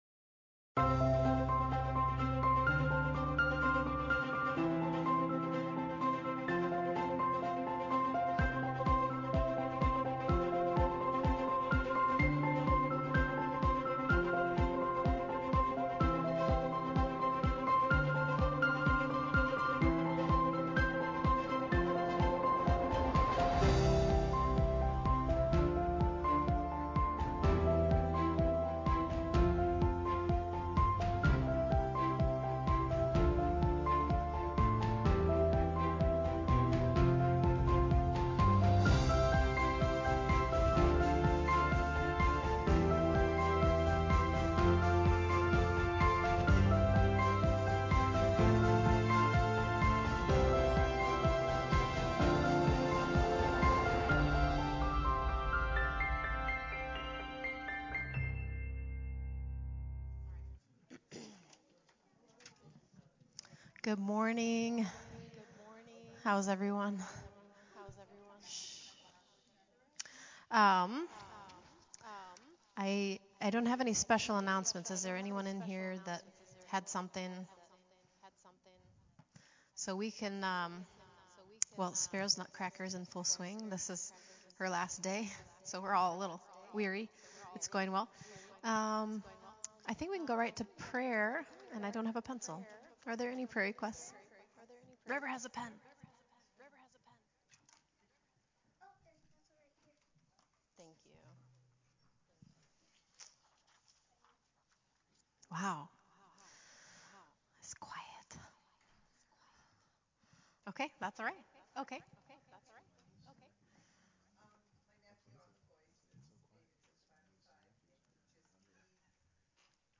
Praise Worship
Week 3 Advent Reading and Candle Lighting